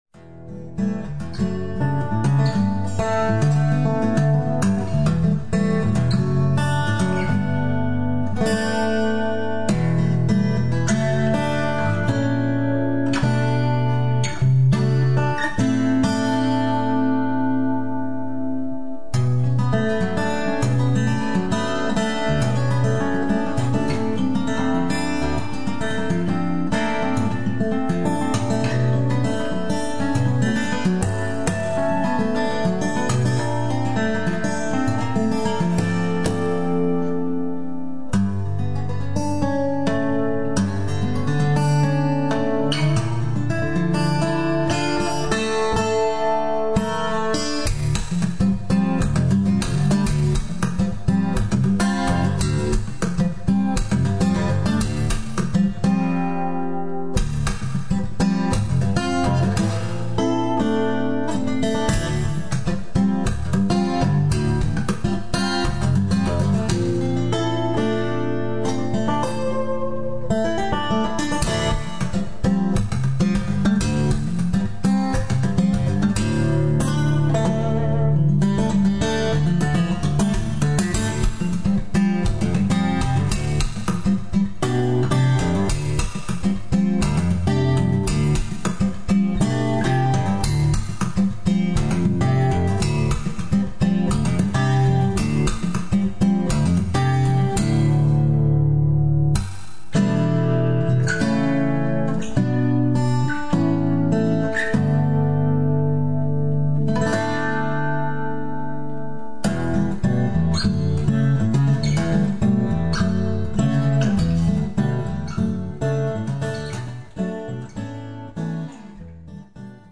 Here are a few samples of my guitar playing: (I plan to add more pictures along with some clips from old bands when I can get time to go through my stored memorabilia)
Acoustic Fingerstyle - Karen Speaks Portuguese (original) This is the middle section of a long piece - highlights my percussive technique with solo fingerstyle.